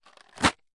描述：在他们进来的纸板箱里摇动热玉米粉蒸丸糖果。 用Tascam DR40录制。